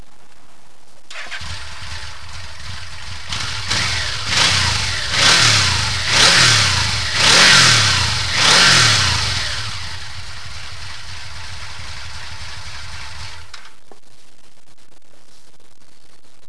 Avec les petites sorties, la Vmax devient quasi inaudible dans le trafic et perd quelques chevaux...